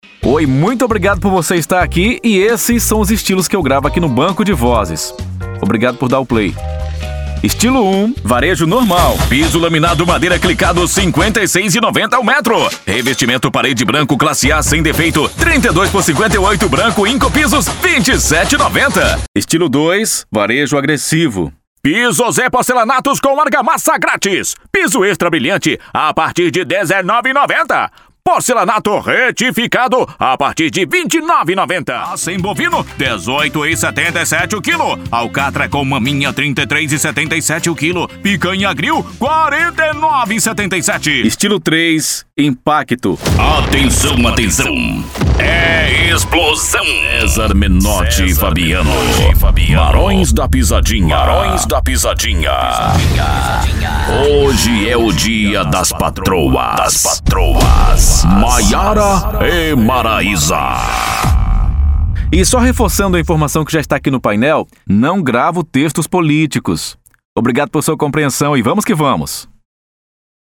Spot Comercial
Vinhetas
Impacto